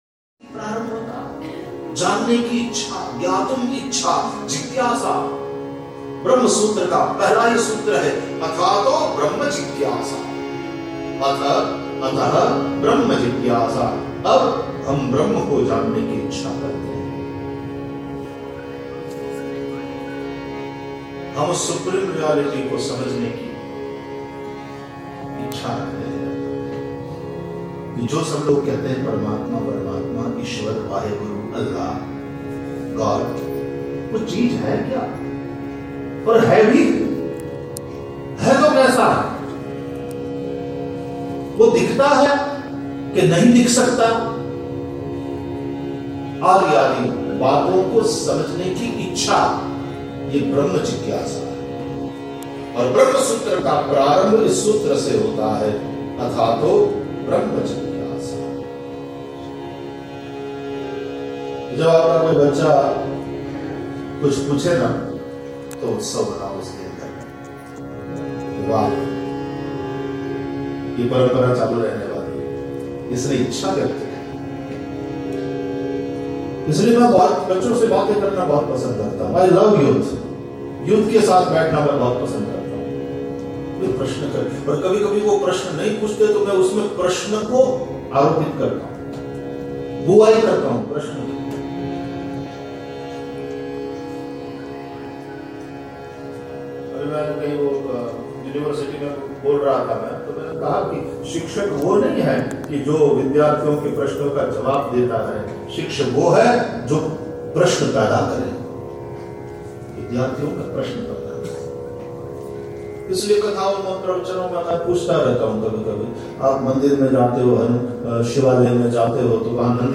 Shri Hanumant Charitra Katha in Calcutta